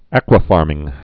(ăkwə-färmĭng, äkwə-)